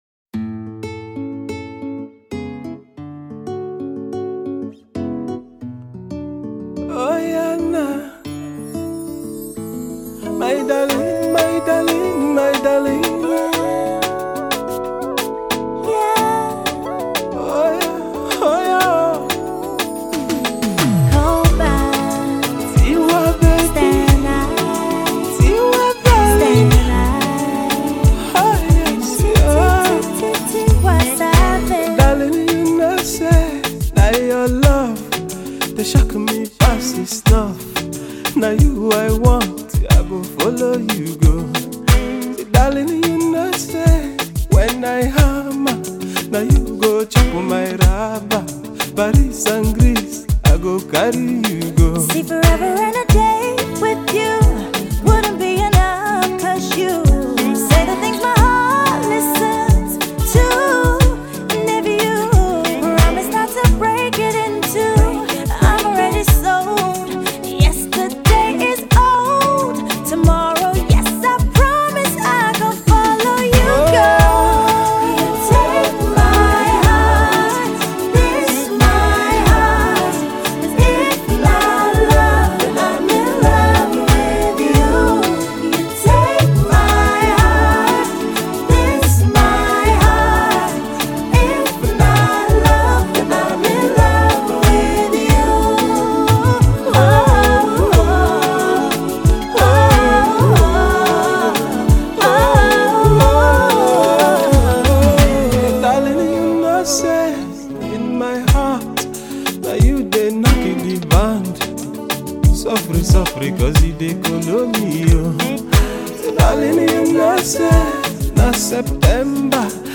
relaxing balladic performances